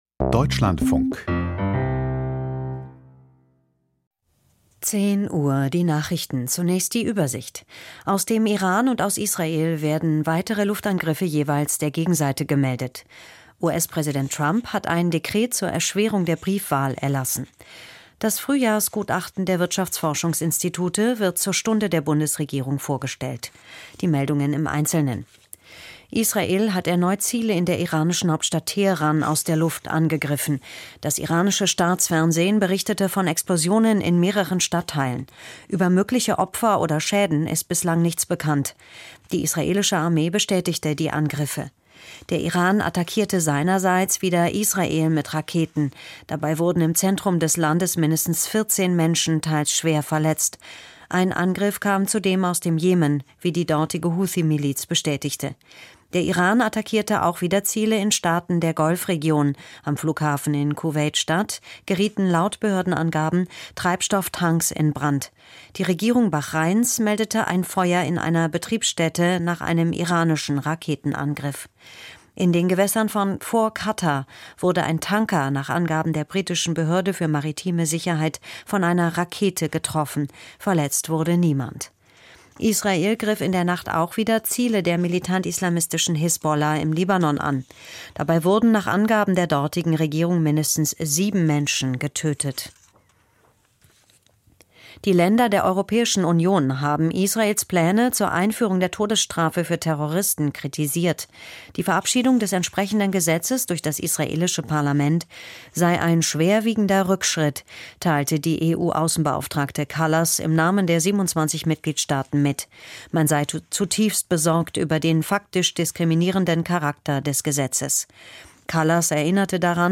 Die Nachrichten vom 01.04.2026, 10:00 Uhr